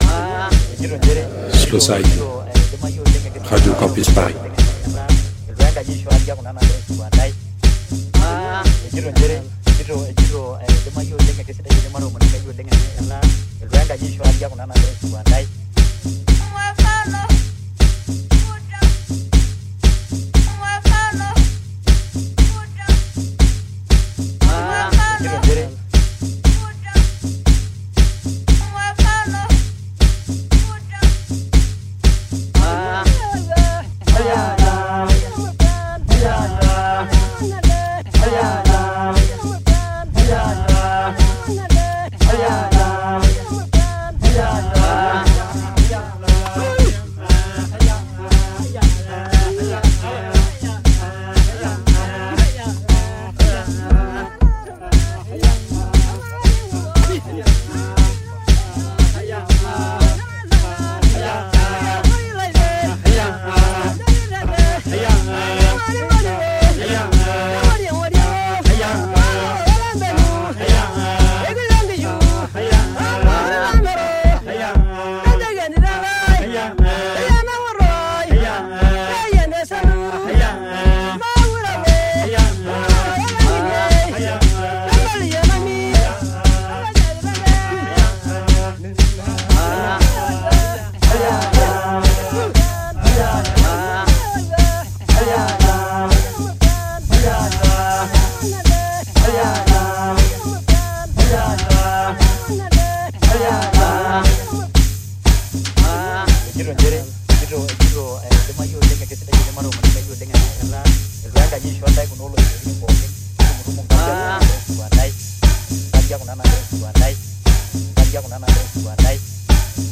Rendez-vous à minuit pour une heure de mix sur le 93.9FM.